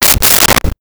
Door French Close
Door French Close.wav